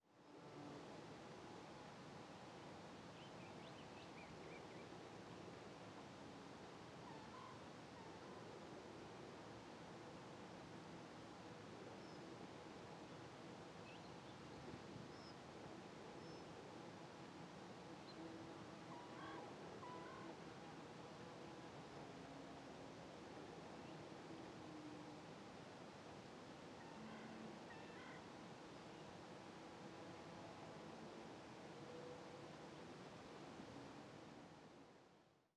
Ambiente de alta montaña
Sonidos: Animales
Sonidos: Rural